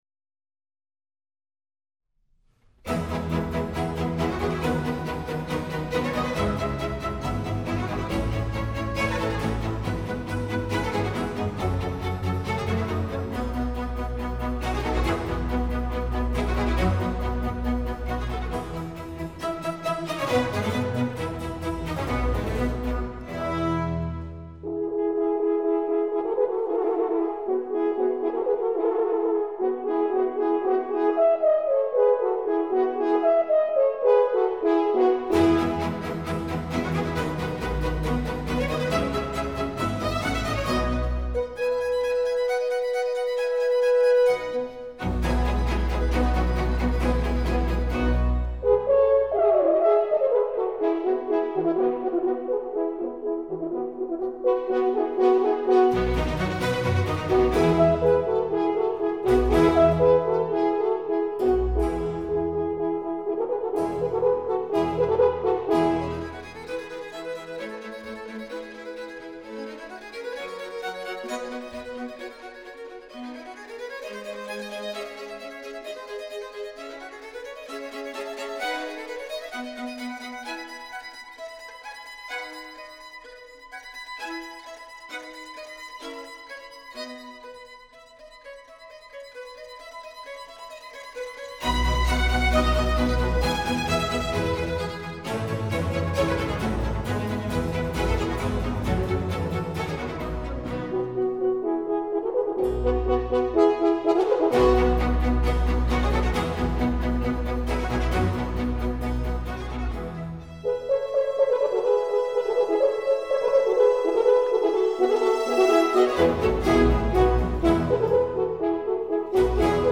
音樂類型：古典音樂
★音樂大獎-世界最佳錄音名作系列 5